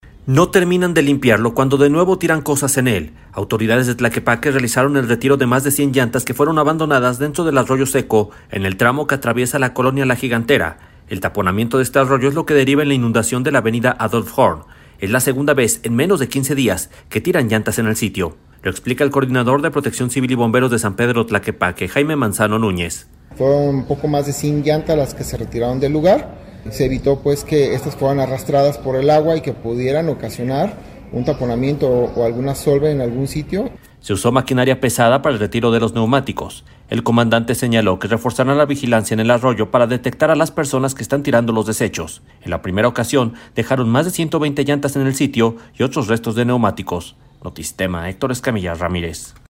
Lo explica el coordinador de Protección Civil y Bomberos de San Pedro Tlaquepaque, Jaime Manzano Núñez.